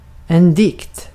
Ääntäminen
IPA: /ˈdɪkt/